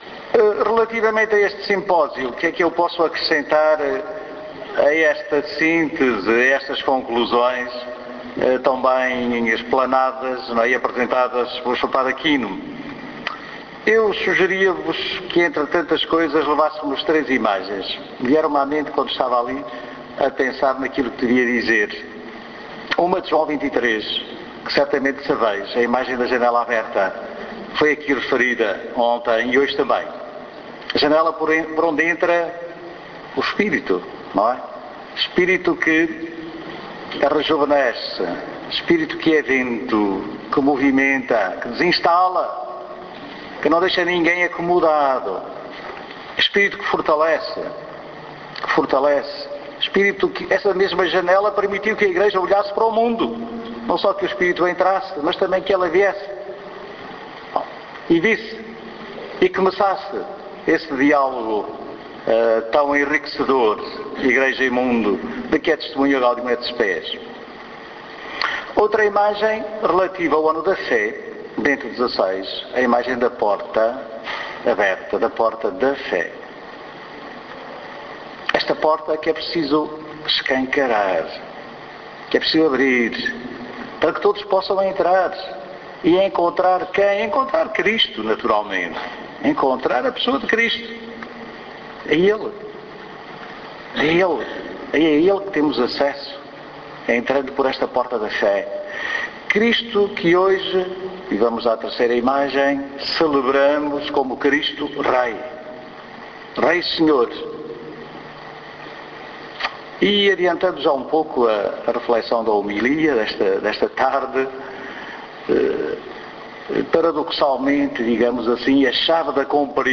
As conclusões do simpósio sobre o Concílio Vaticano II que a Igreja Católica do Algarve realizou este fim de semana no salão paroquial de São Luís, em Faro, participado por cerca de 300 católicos de vários pontos do Algarve, apontam diversos “desafios”.
Encerramento_simposio_vaticano.mp3